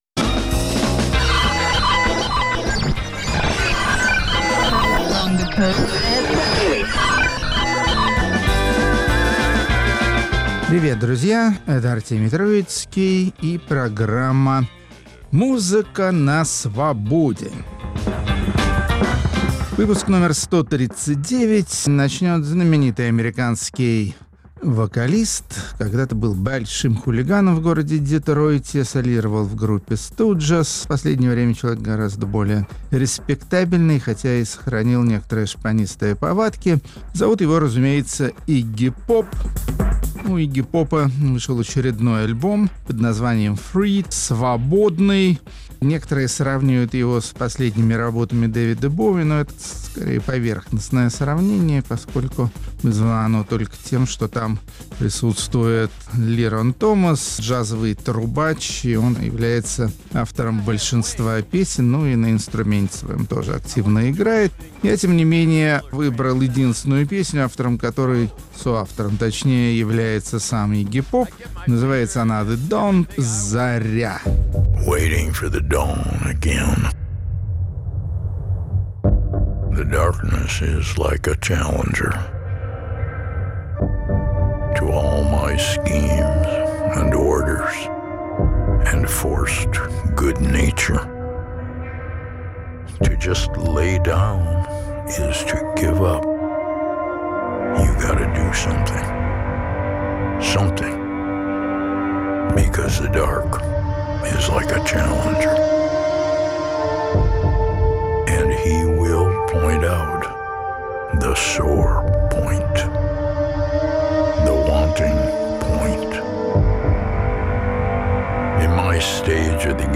Музыка на Свободе. Всё, что блестит. Артемий Троицкий представляет последние летние песни